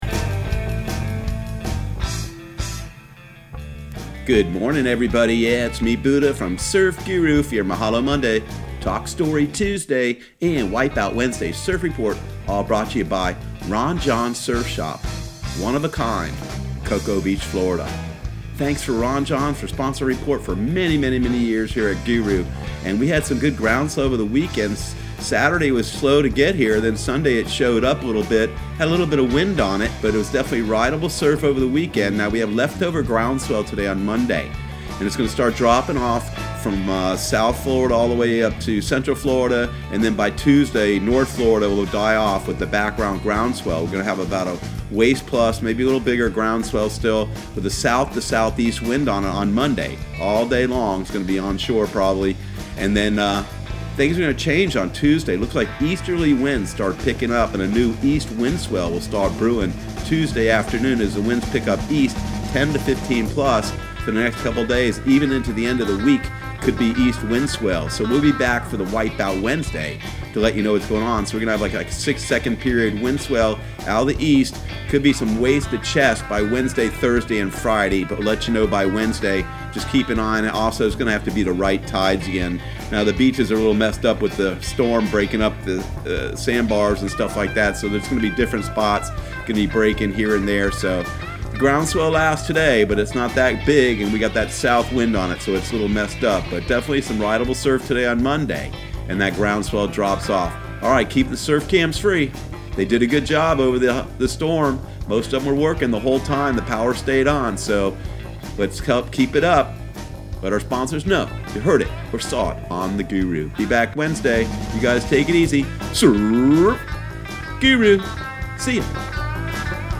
Surf Guru Surf Report and Forecast 09/09/2019 Audio surf report and surf forecast on September 09 for Central Florida and the Southeast.